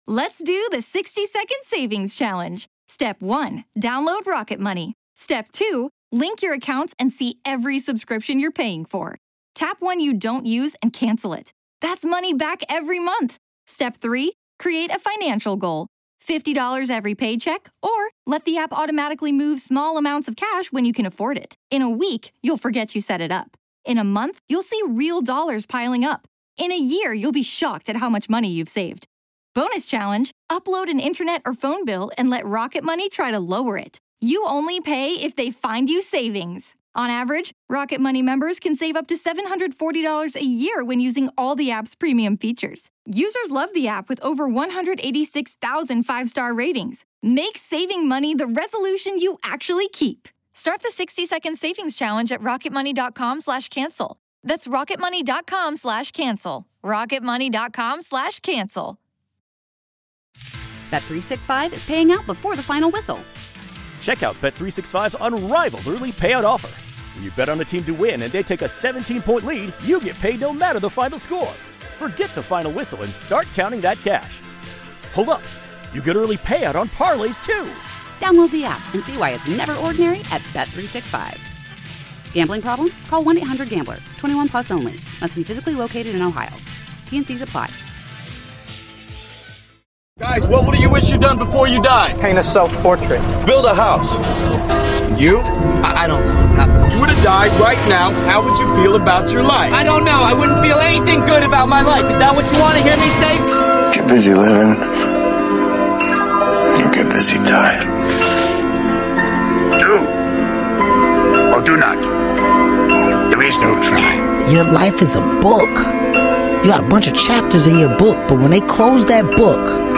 This Episode is about the relentless and inspiring David Goggins. If you ever doubted your potential, this motivational speech is for you.
David Goggins, known for his unbreakable spirit and powerful words, delivers a speech that will push you to realize that you are meant for more.